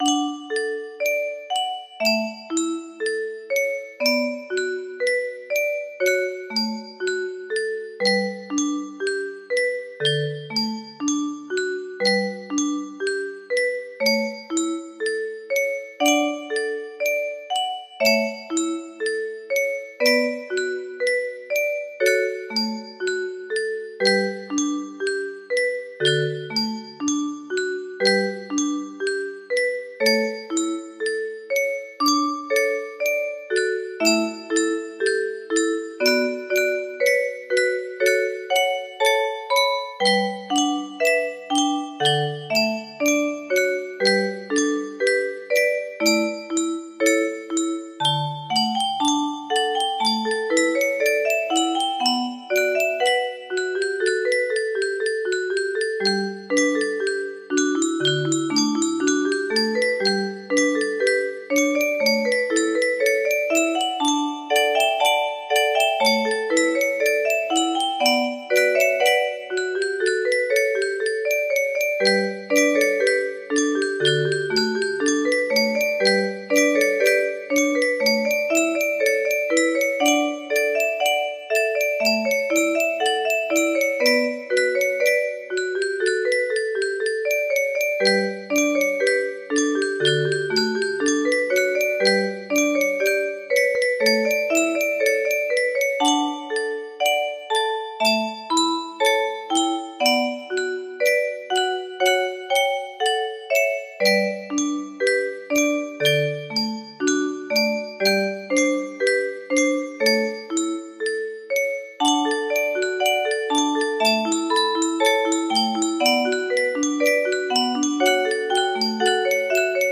Canon in D 30 note music box melody